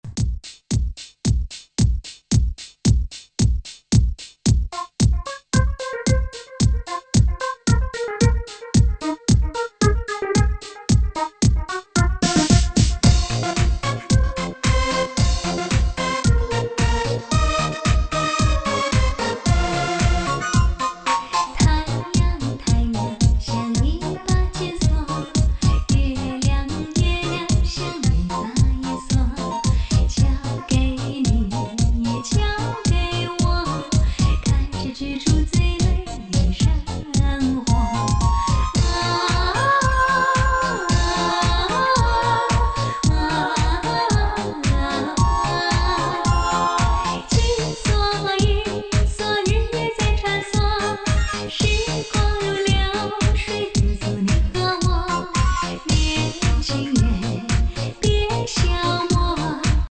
[17/1/2025]6声道歌曲